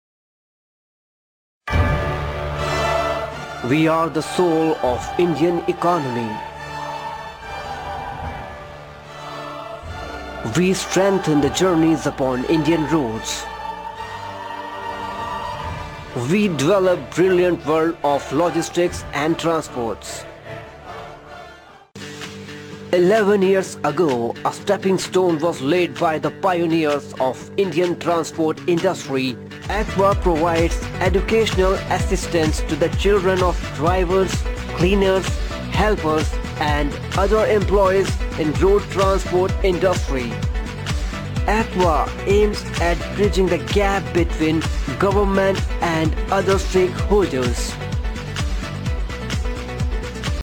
a professional voice over artist with good command over hindi , english and punjabi
Sprechprobe: Sonstiges (Muttersprache):